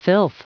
Prononciation du mot filth en anglais (fichier audio)